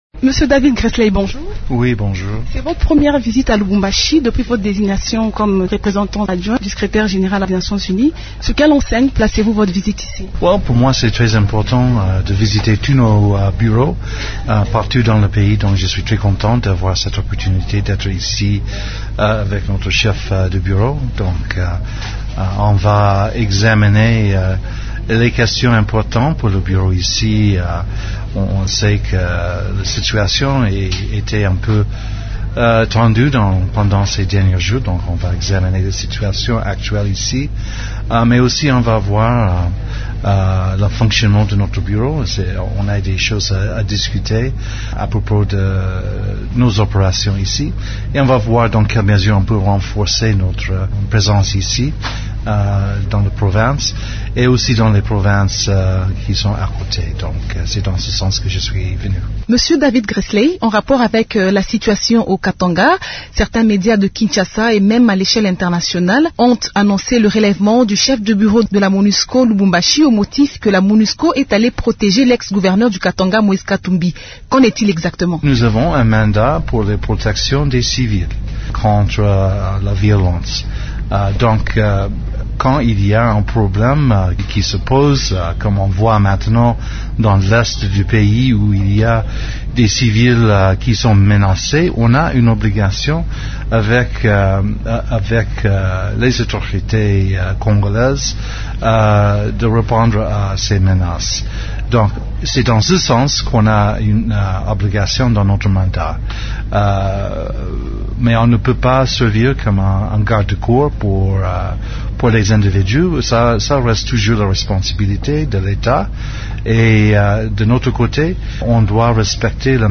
Invité de Radio Okapi mercredi 12 mai, le diplomate onusien a réitéré la volonté de la MONUSCO d’appuyer le processus électoral congolais.